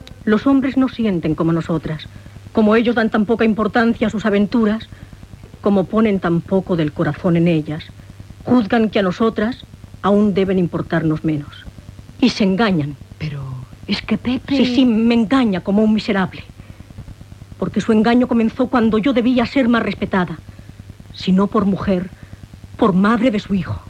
Fragment on una dona es lamenta que el seu marit és un adúlter. Adaptació radiofònica de l'obra "Rosas de otoño" (1938) de Jacinto Benavente.
Ficció